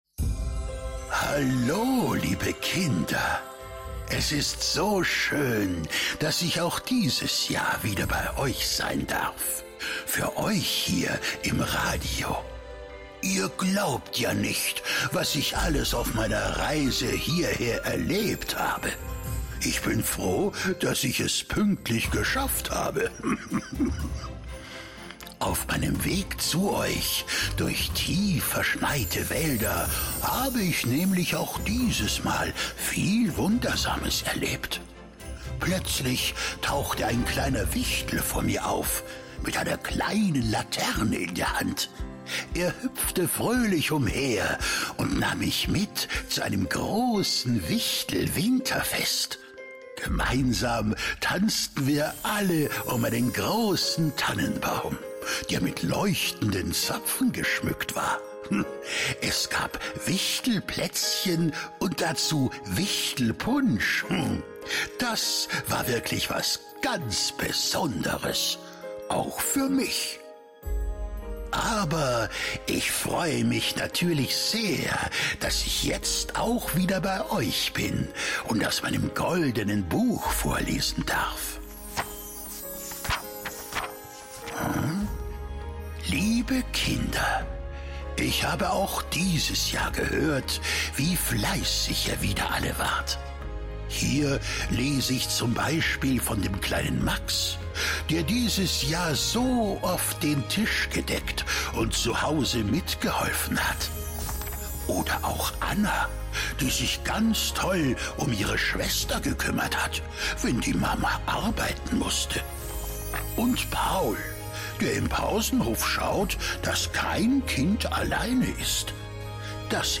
Wir wissen, dass der Nikolaus nicht jedes Kind persönlich besuchen kann und deswegen wird er wieder bei uns eine ganz besondere Nikolaus Ansprache für eure Kids im Radio halten - und eure Kinder werden hoffentlich wieder ganz gespannt zuhören.
Nikolausansprache 2024 in BAYERN 3
Nikolausansprache_2024.mp3